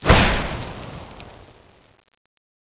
icicle_launch.wav